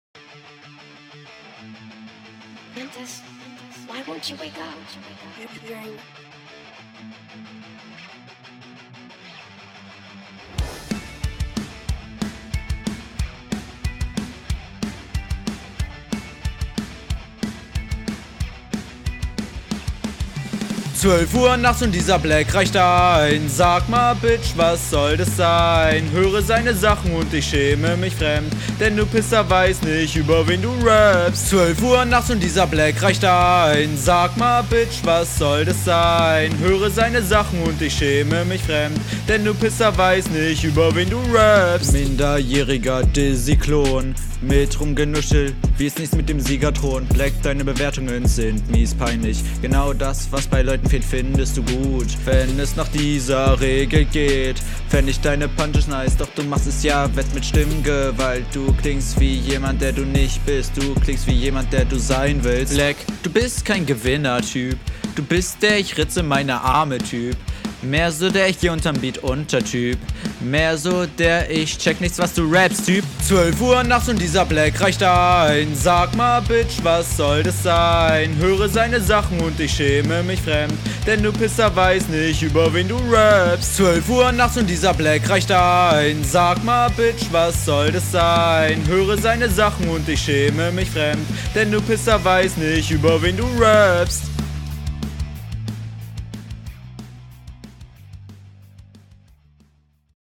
ein Pop rock trap type beat bitte enttäusch mich nicht UND DU HAST MICH SEHR …
du liegst krass über dem beat. der wirkt viel zu leise und du harmonierst nicht …
hook ist absturz. schlechte reimsetzung. relativ on beat aber stimmeinsatz komisch.